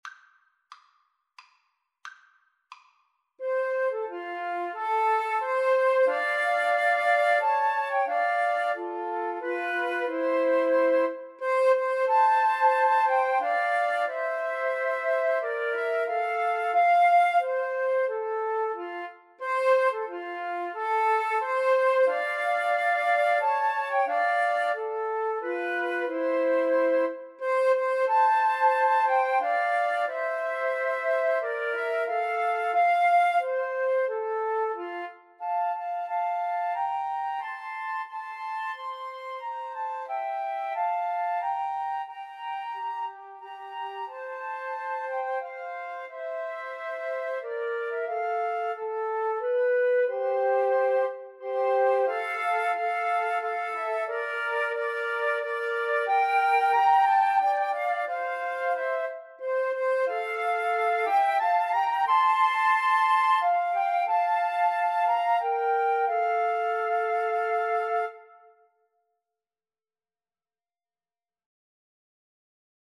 Free Sheet music for Flute Trio
3/4 (View more 3/4 Music)
F major (Sounding Pitch) (View more F major Music for Flute Trio )
Maestoso = c.90
Flute Trio  (View more Intermediate Flute Trio Music)
Traditional (View more Traditional Flute Trio Music)